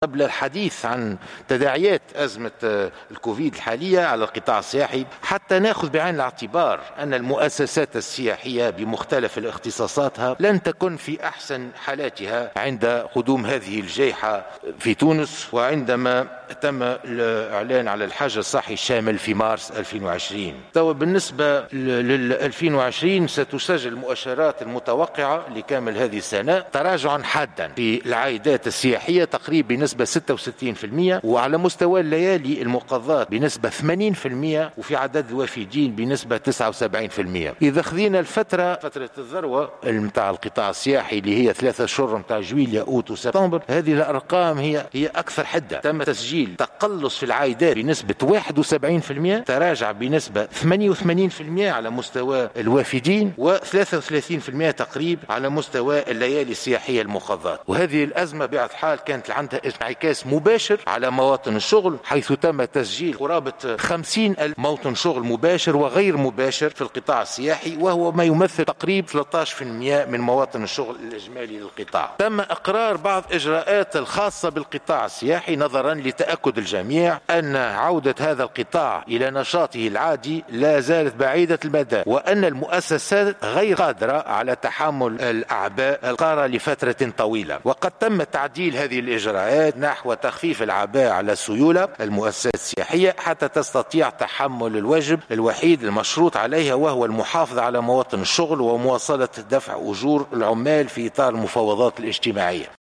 وإعتبر الوزير في كلمته الإفتتاحية خلال جلسة الإستماع له اليوم في البرلمان ، أن المؤسسات السياحية لم تكن في أحسن حالاتها عند قدوم جائحة الكوفيد، فالأزمة الهيكلية والأزمة الصحية زجّت بالقطاع في أزمة غير مسبوقة ، مشيرا إلى أن عودة نشاط القطاع بعيدة المدى وفق تعبيره.